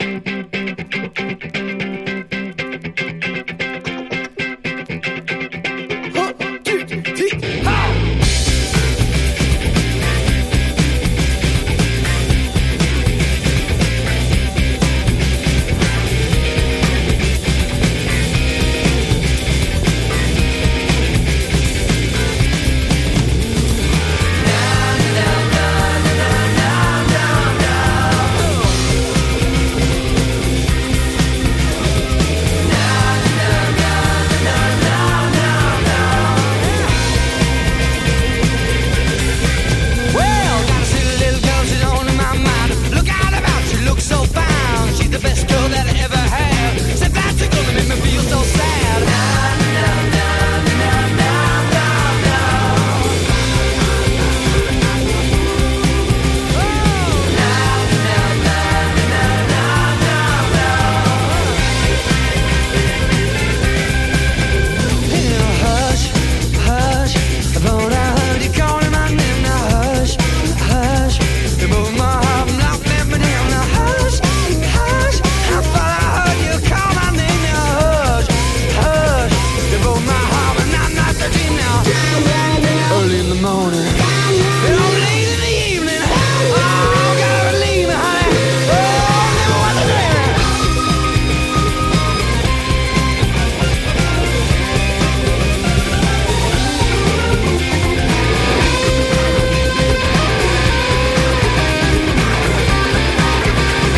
ザクザクと刻まれるギター・カッティングのイントロから沸々と高揚感を煽られる